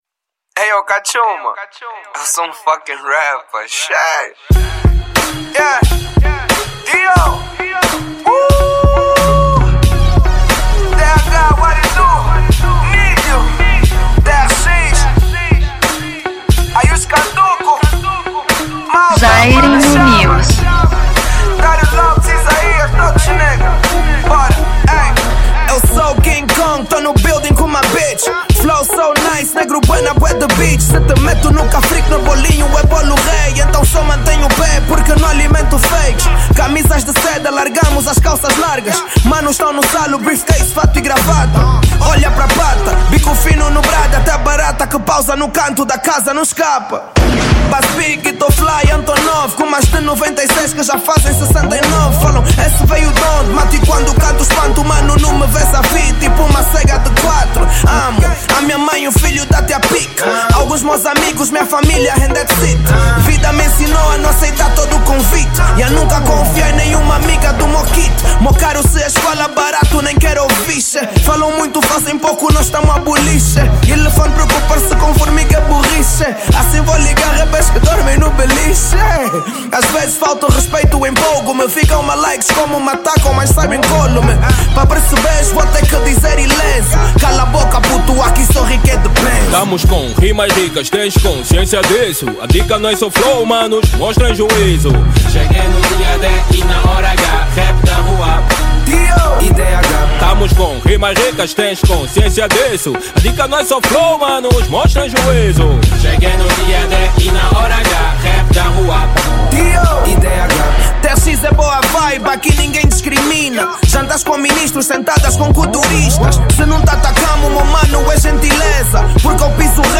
Estilo: Hip Hop Ano